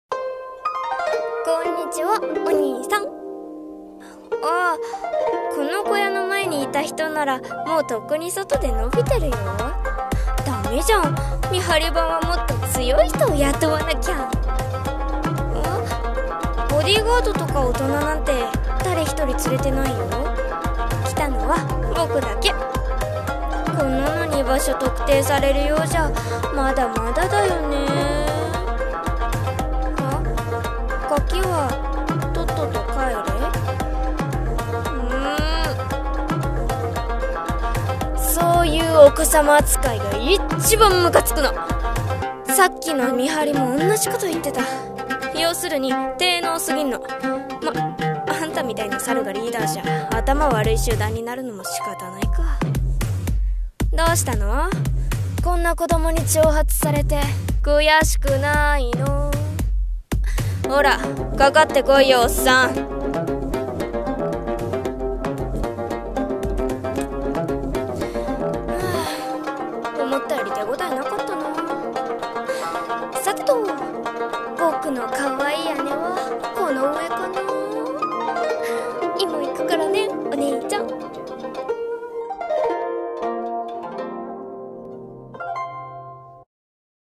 【朗読】